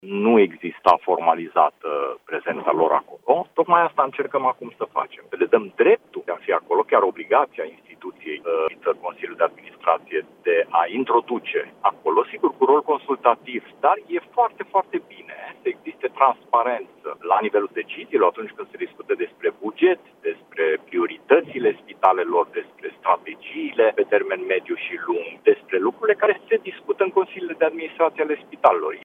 Adrian Wiener, deputat USR și co-inițiator al proiectului: „Nu există formalizată prezența lor acolo și tocmai asta încercăm noi acum să facem”